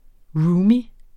Udtale [ ˈɹuːmi ]